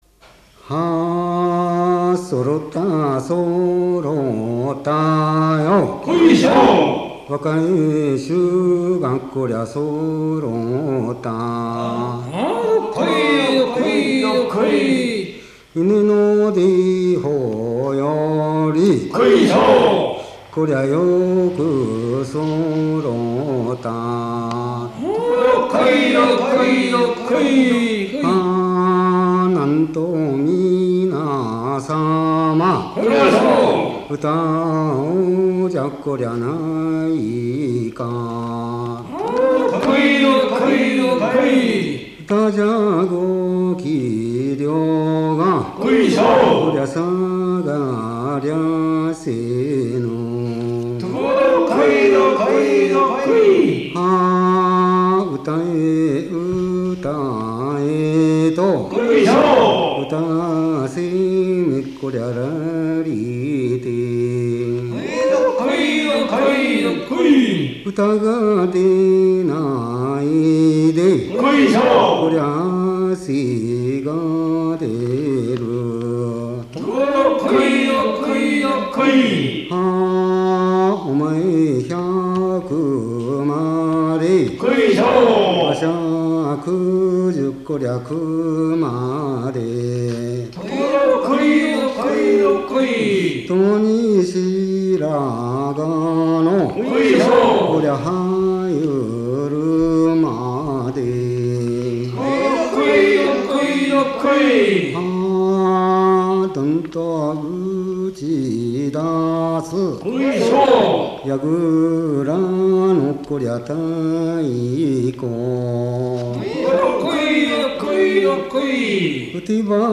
花房甚句 座興歌